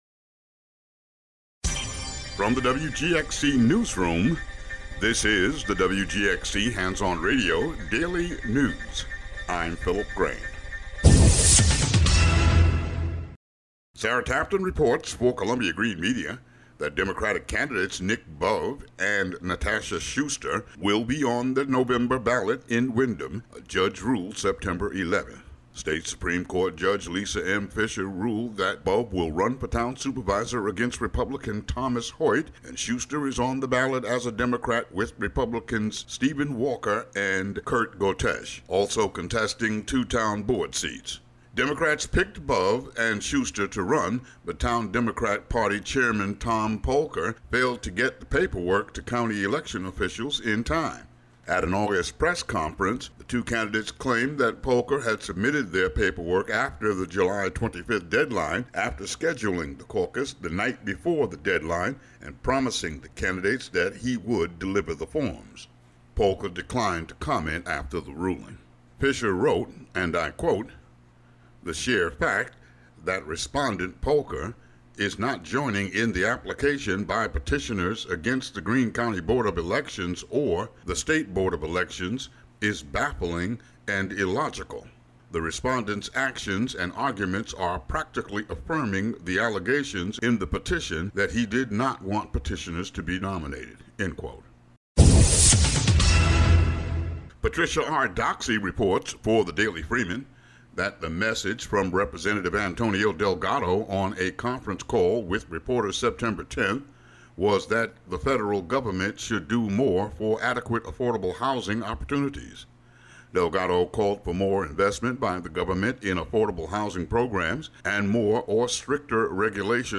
Today's local news.